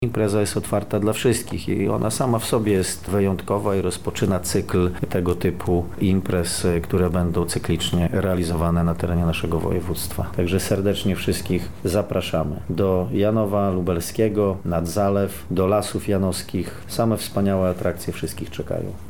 – mówi Członek Zarządu Województwa Lubelskiego Jarosław Kwasek.